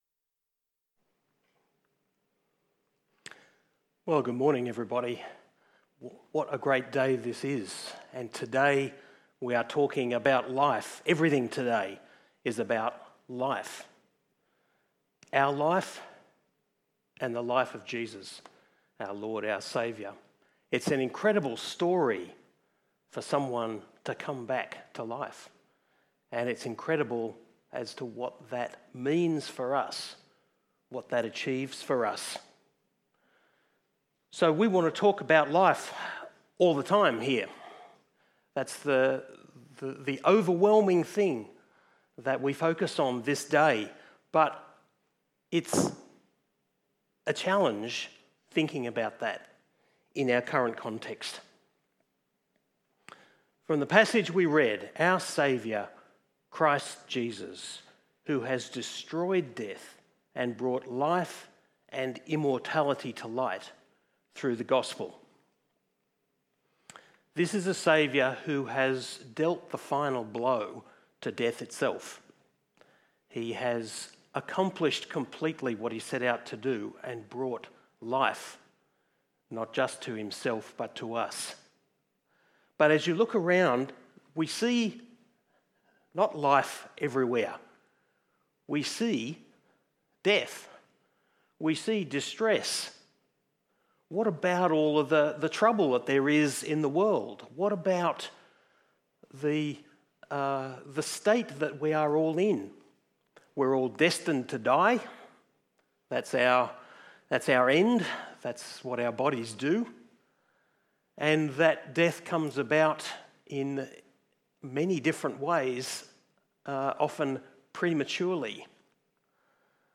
KPCC Sermons | Kings Park Community Church
Easter Sunday 2020